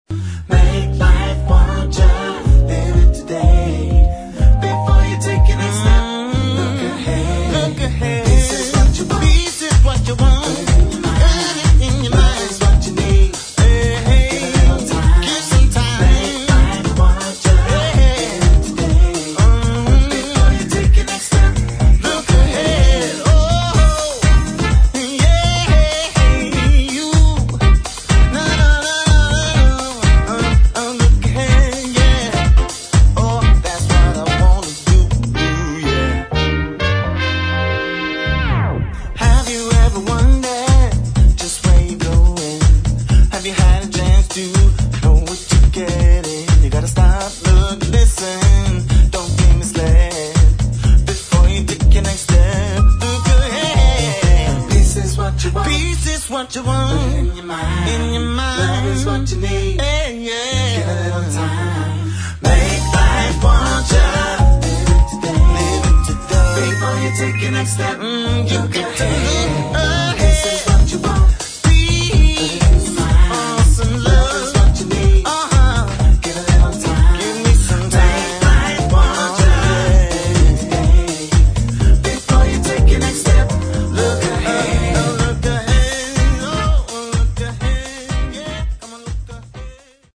[ HOUSE | DISCO ]